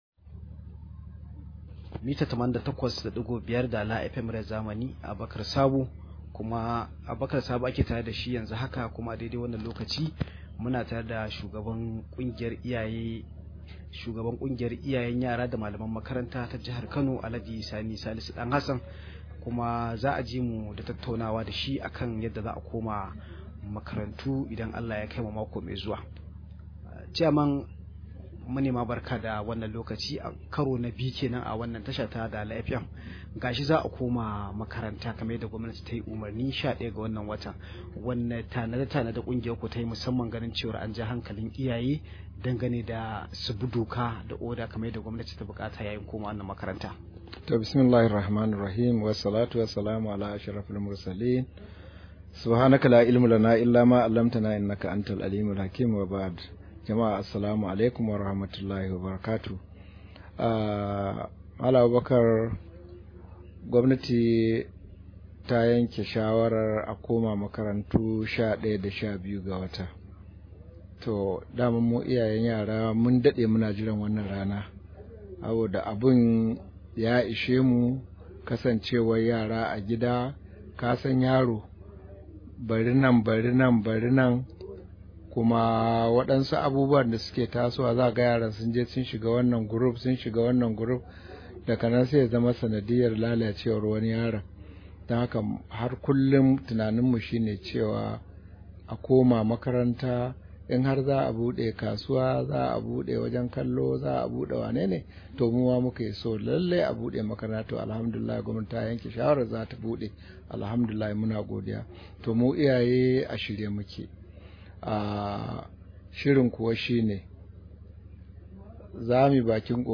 Rahoto: Za mu tabbatar dalibai sun bi dokokin NCDC – PTA